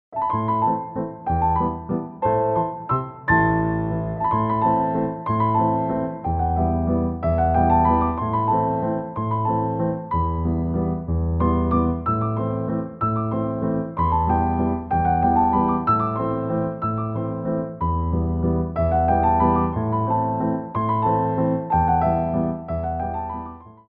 3/4 (8x8)